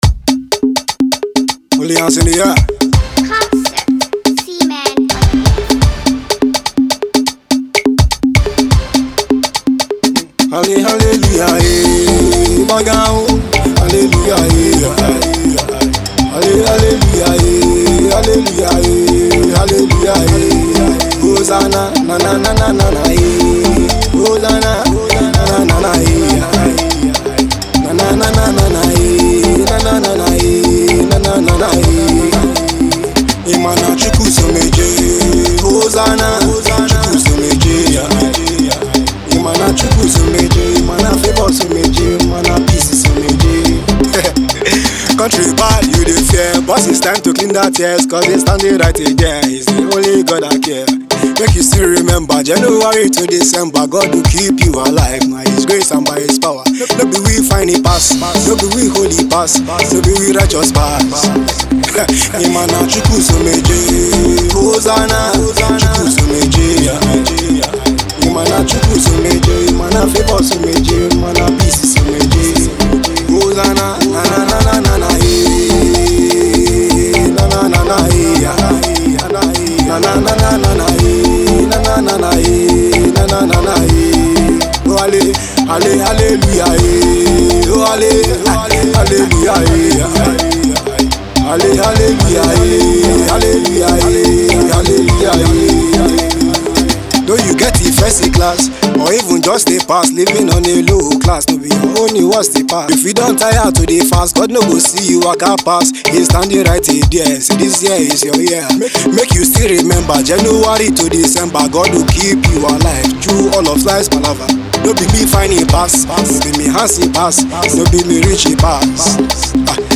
gospel artiste